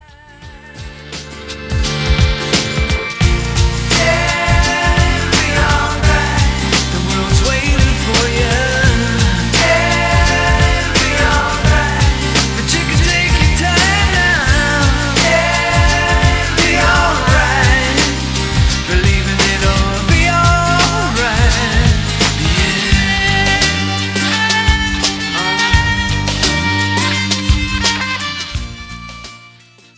hypnotic music
guitars
keyboards
background vocals